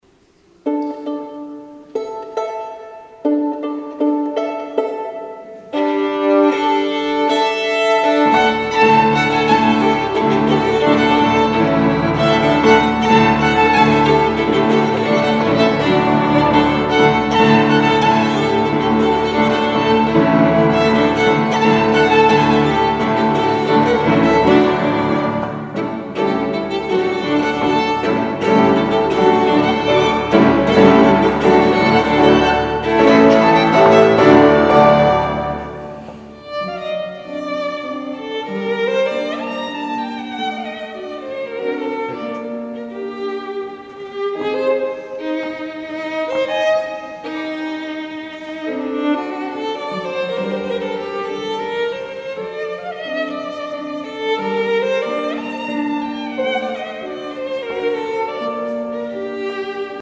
This is the live record. Sorry for some ambiance noises.